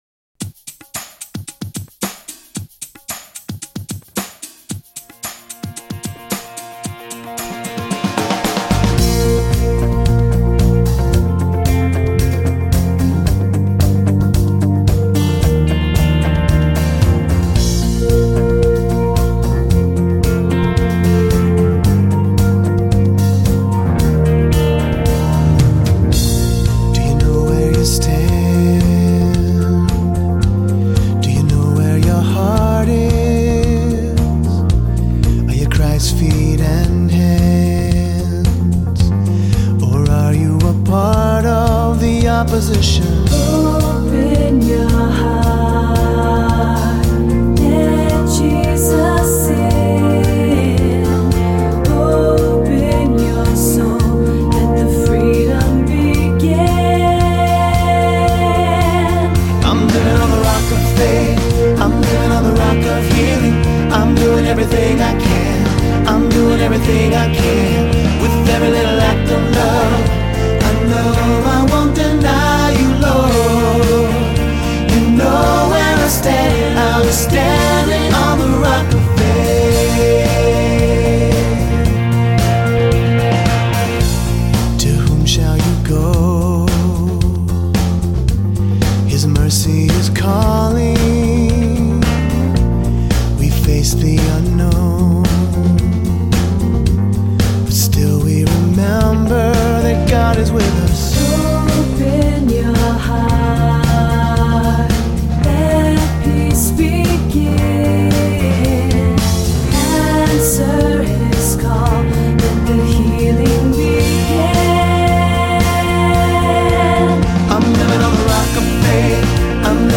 Voicing: 2-part Choir, assembly,Soloist or Soloists